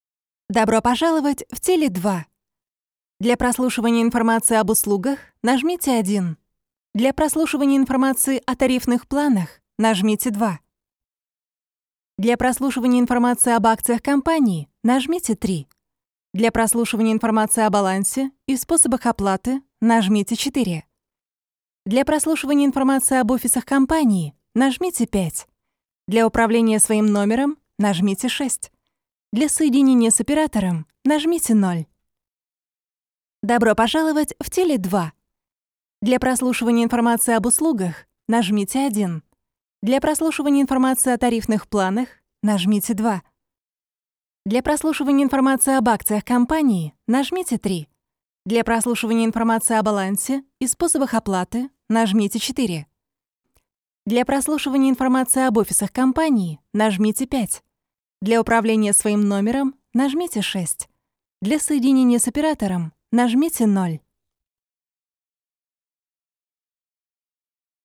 начитка